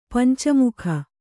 ♪ panca mukha